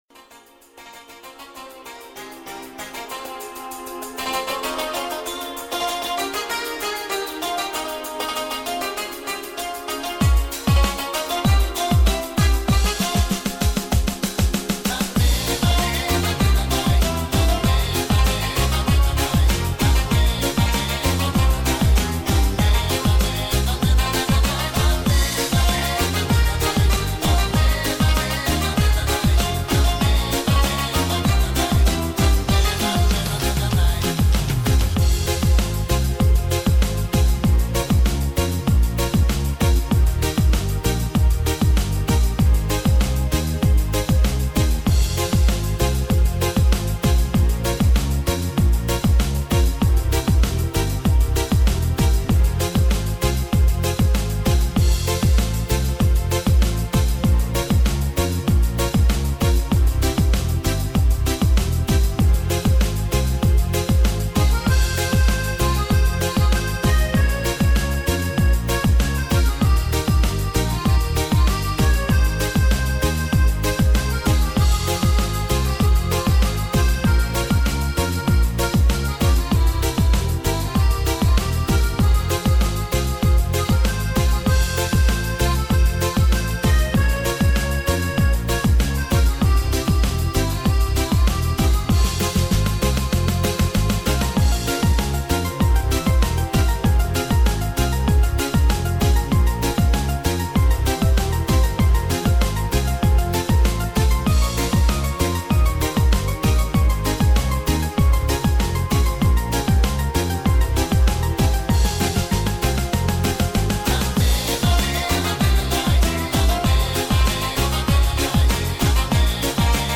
минусовка версия 54037